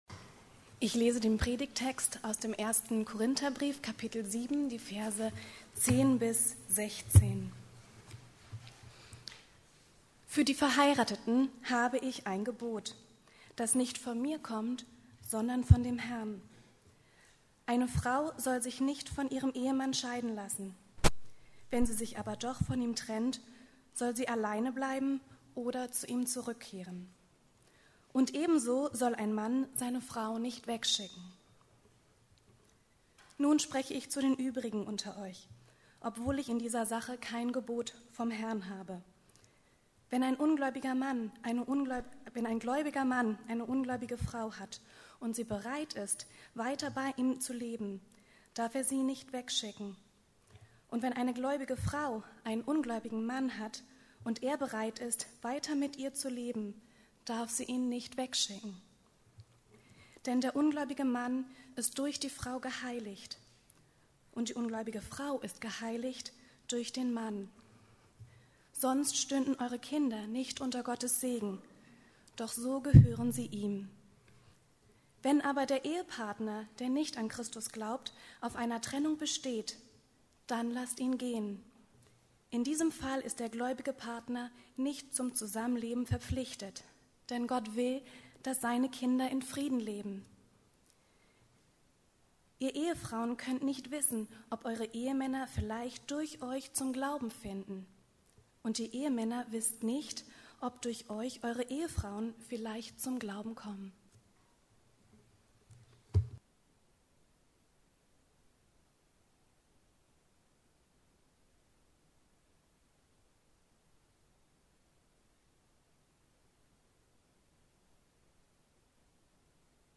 Verschiedene Aspekte der Ehescheidung ~ Predigten der LUKAS GEMEINDE Podcast